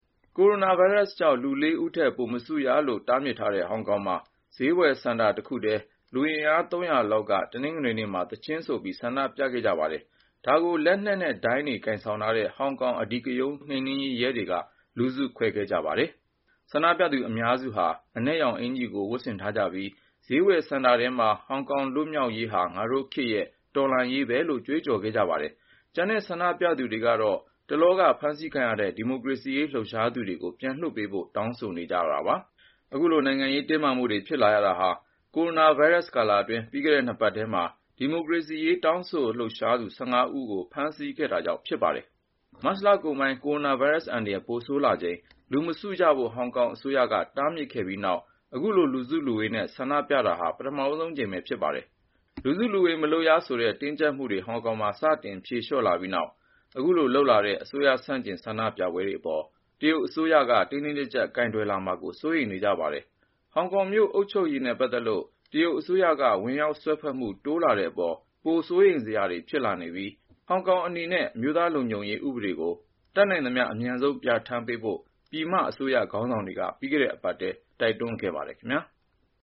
ကိုရိုနာဗိုင်းရပ်စ်ကြောင့် လူ ၄ ဦးထက် ပိုမစုရလို့ တားမြစ်ထားတဲ့ ဟောင်ကောင်မှာ ဈေးဝယ်စင်တာတခုထဲ လူအင်အား ၃၀၀ လောက်ရှိတဲ့ ဆန္ဒပြသူတွေက တနင်္ဂနွေနေ့မှာ သီချင်းဆိုပြီး ဆန္ဒပြခဲ့ကြပါတယ်။